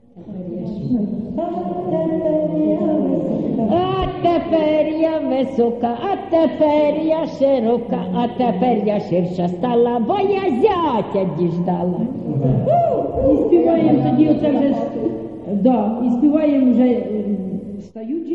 ЖанрВесільні